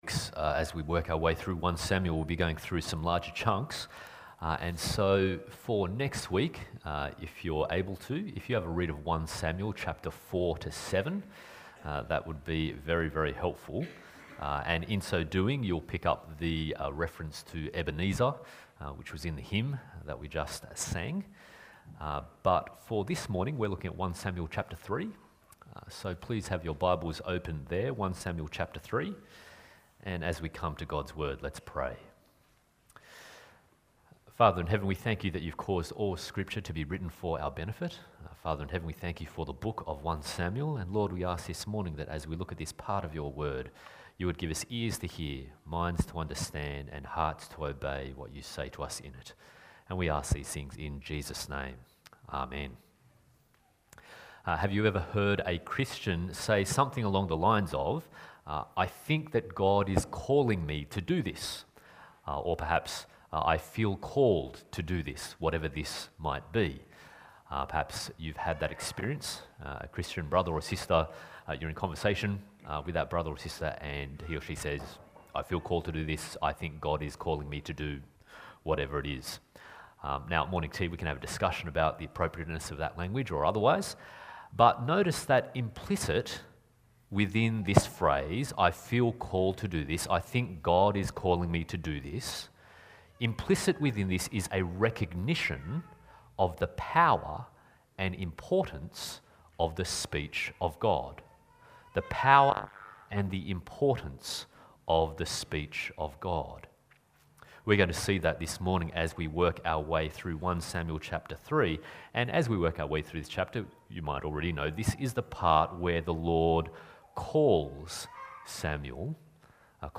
1 Samuel Passage: 1 Samuel 3:1-21, Deuteronomy 18:15-22, Mark 9:2-8 Service Type: Sunday Morning « The Failure of Eli’s House The Powerful